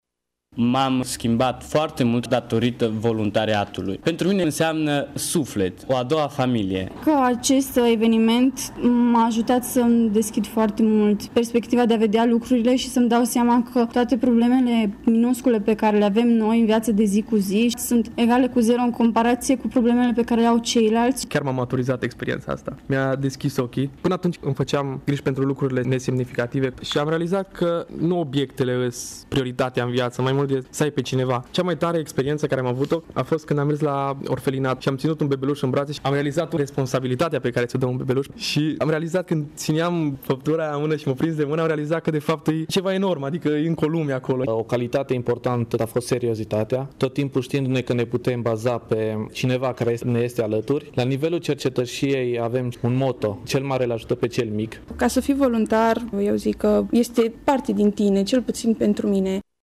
Astfel, studenții